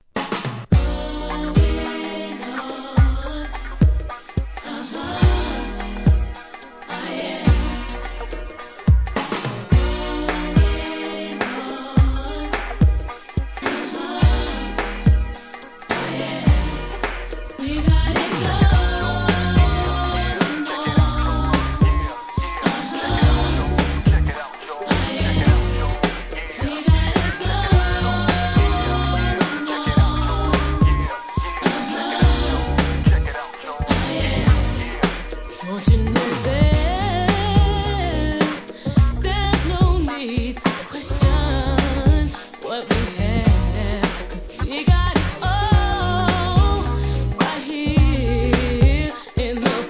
HIP HOP/R&B
爽快な跳ねナンバー!!